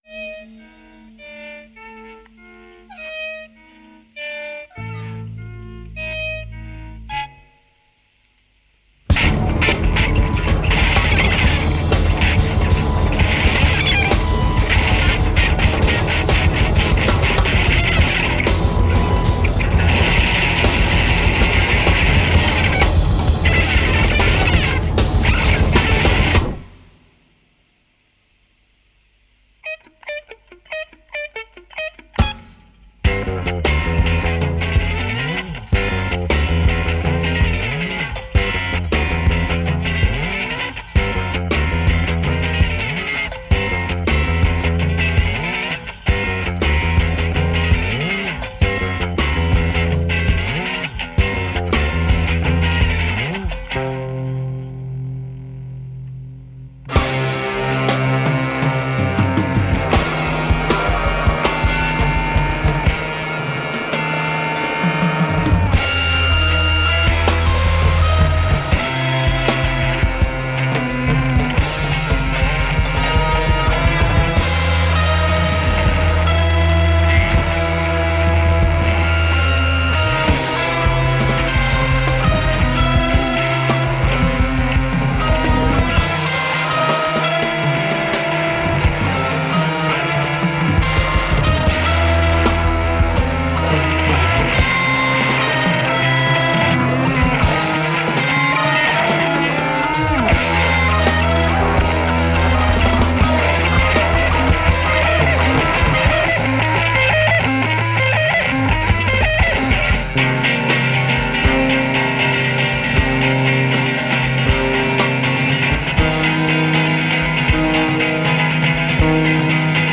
the bridge between Progressive and Punk